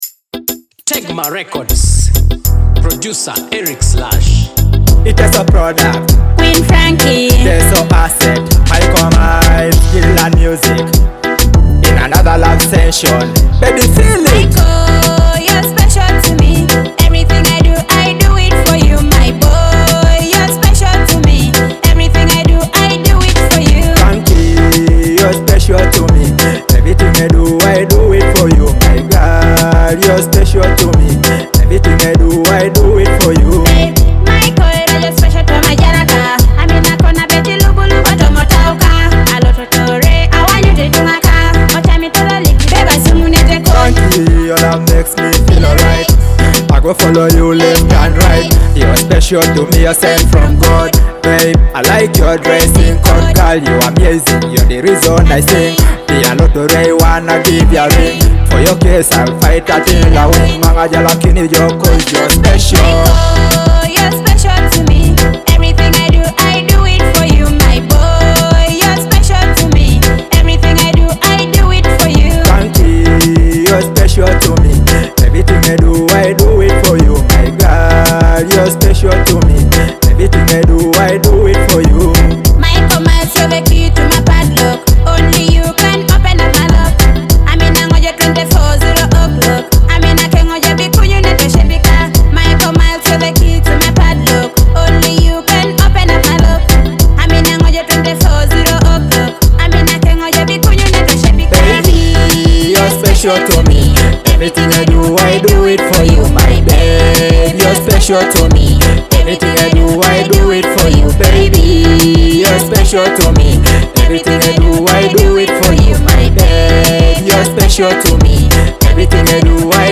a dynamic Teso music collaboration .